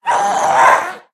Minecraft Version Minecraft Version snapshot Latest Release | Latest Snapshot snapshot / assets / minecraft / sounds / mob / ghast / scream5.ogg Compare With Compare With Latest Release | Latest Snapshot
scream5.ogg